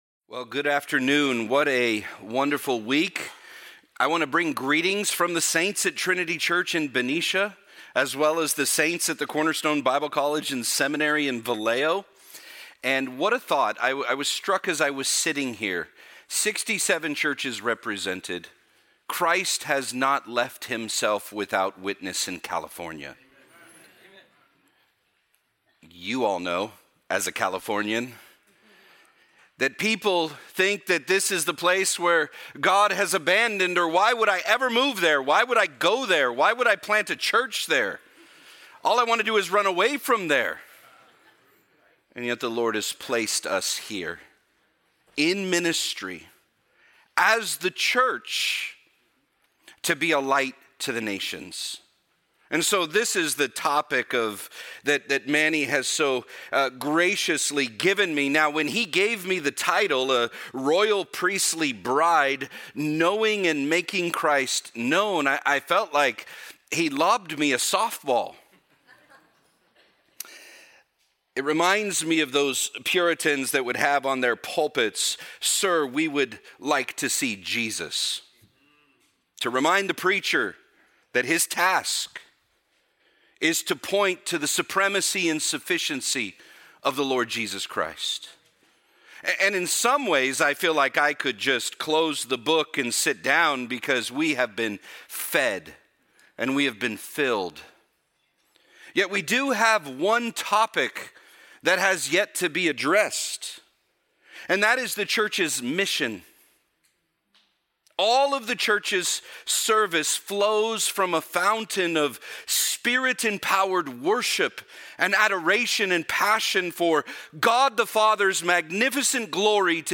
A Conference on the Local Church and Her Lifeblood